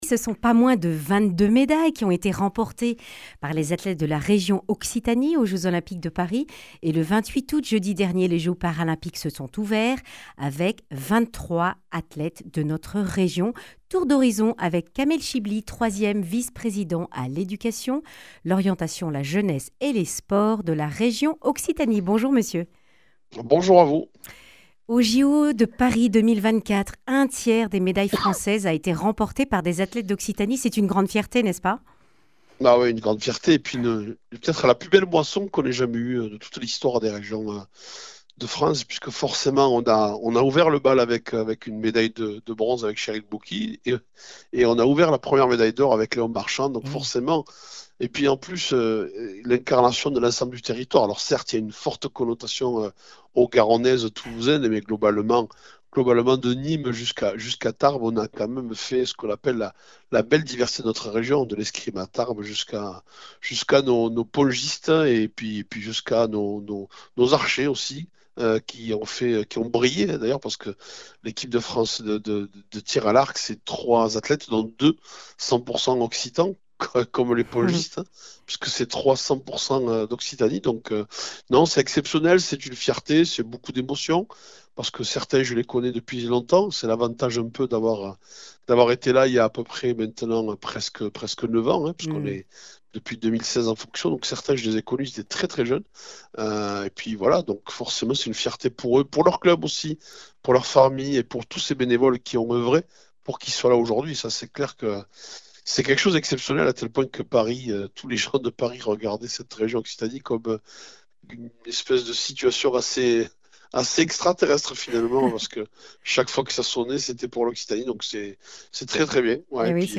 Comment la Région les accompagne t-elle ? Eléments de réponse avec Kamel Chibli, 3ème vice-président à l’éducation, l’orientation, la jeunesse et les sports de la Région Occitanie.